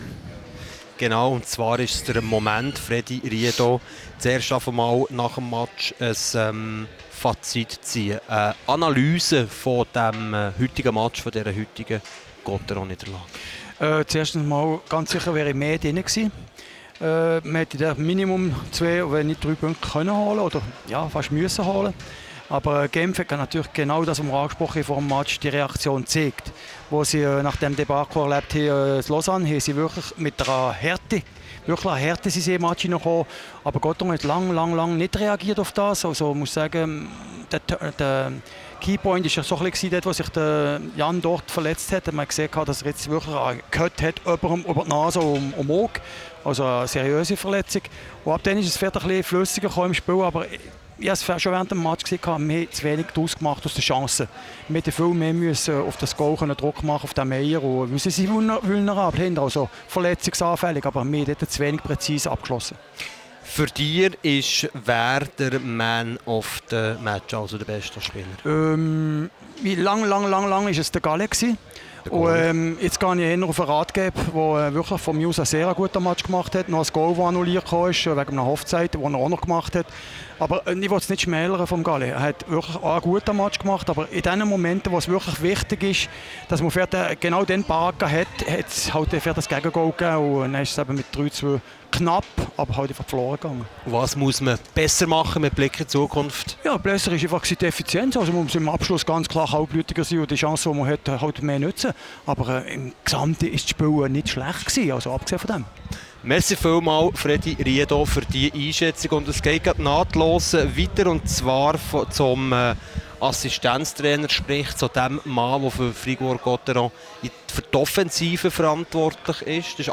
Spielanalyse
Interviews